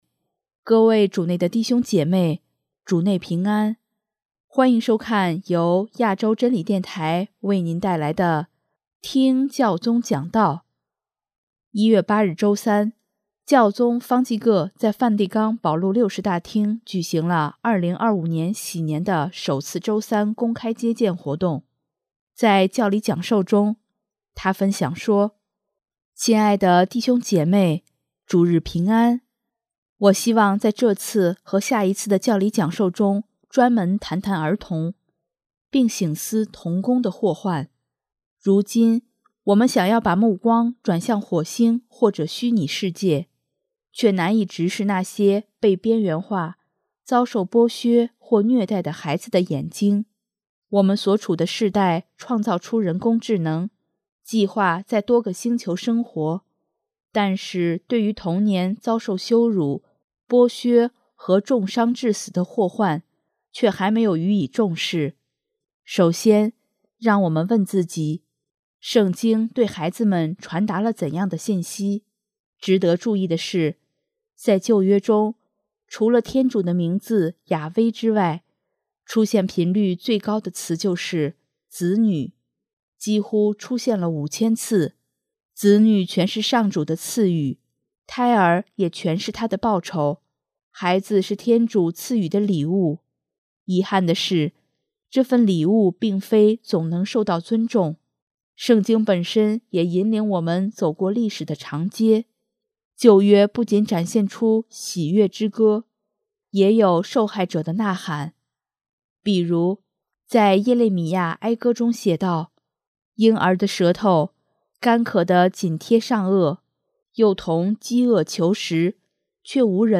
1月8日周三，教宗方济各在梵蒂冈保禄六世大厅举行了2025禧年的首次周三公开接见活动，在教理讲授中，他分享说：